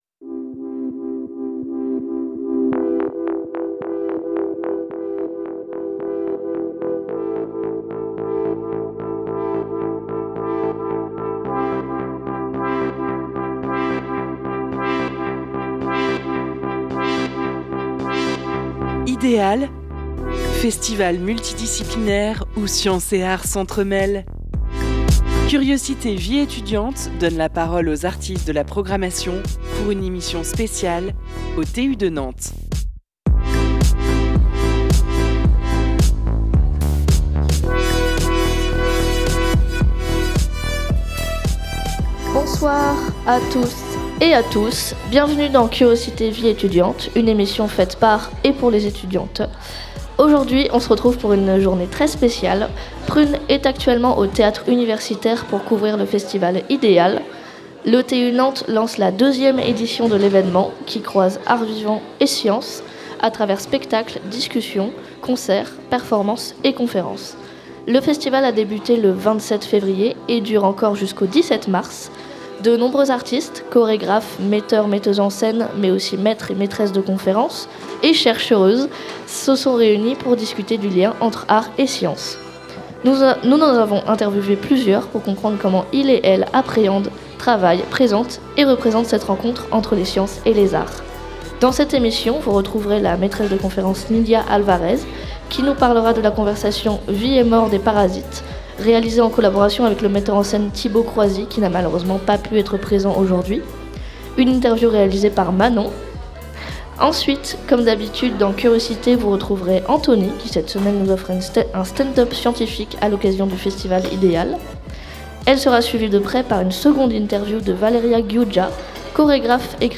Deuxième édition du festival Idéal au TU de Nantes, et une fois encore Prun' est de la partie.
Nous avons interviewé plusieurs d'entre elleux, pour comprendre comment iels appréhendent, travaillent, présentent et représentent cette rencontre.